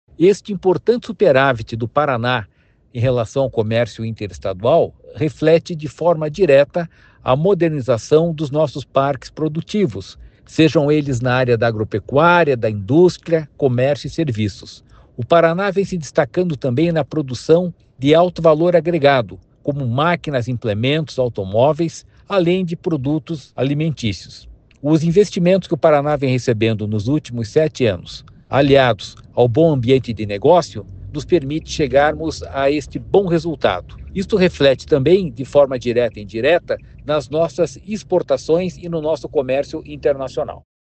Sonora do diretor-presidente do Ipardes, Jorge Callado, sobre o superávit recorde de R$ 144 bilhões no comércio interestadual em 2024